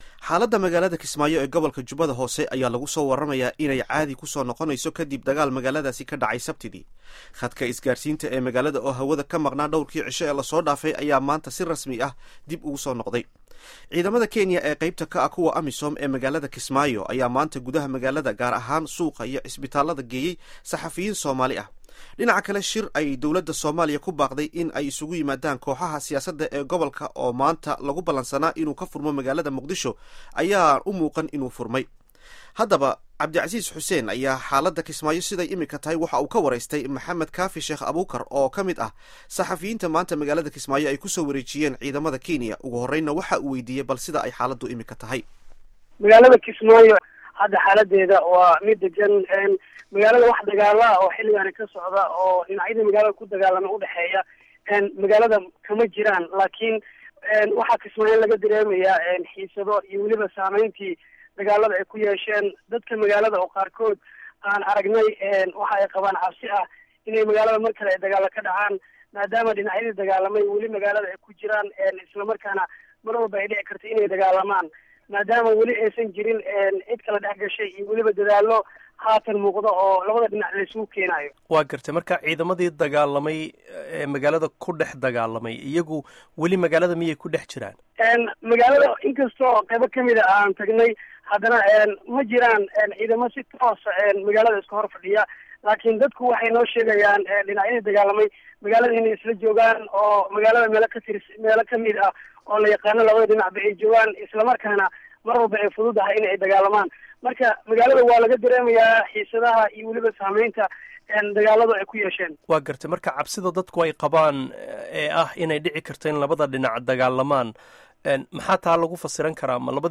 Waraysiga Xaaladda Kismaayo